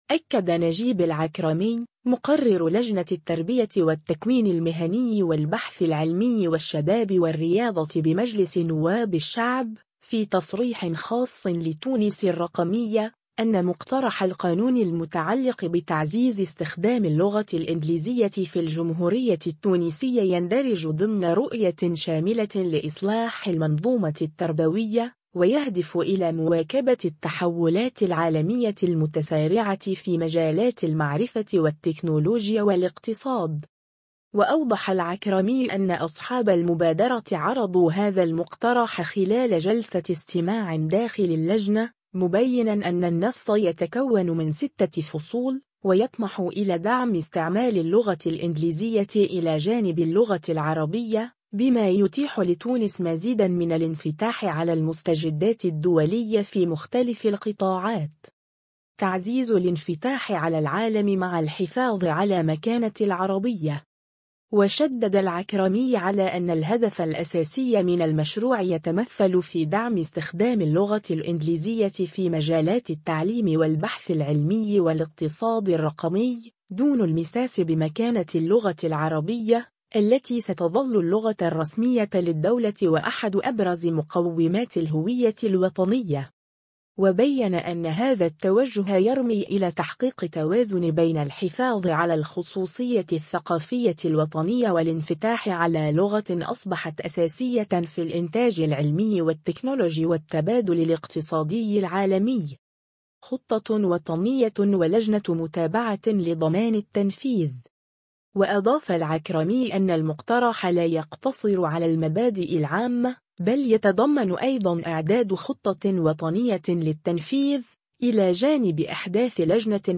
أكد نجيب العكرمي، مقرر لجنة التربية والتكوين المهني والبحث العلمي والشباب والرياضة بمجلس نواب الشعب، في تصريح خاص لـ”تونس الرقمية”، أن مقترح القانون المتعلق بتعزيز استخدام اللغة الإنجليزية في الجمهورية التونسية يندرج ضمن رؤية شاملة لإصلاح المنظومة التربوية، ويهدف إلى مواكبة التحولات العالمية المتسارعة في مجالات المعرفة والتكنولوجيا والاقتصاد.